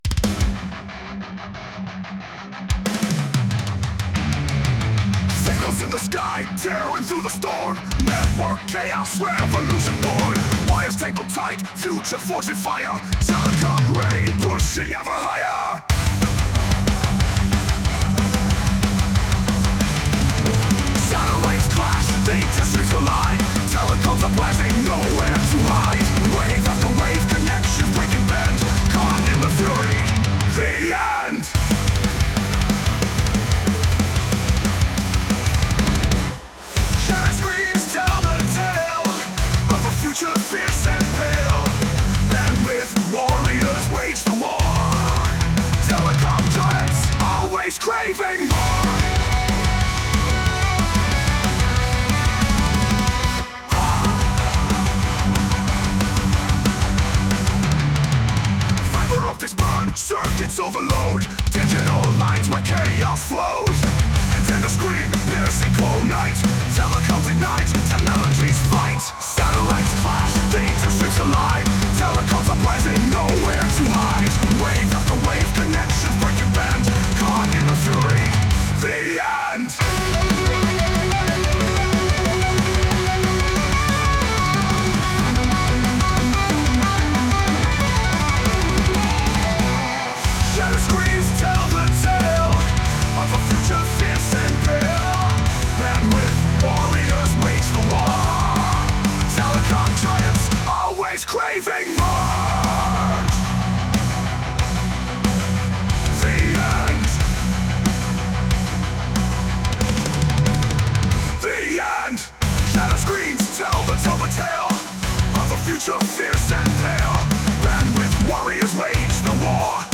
But it’s pretty funny.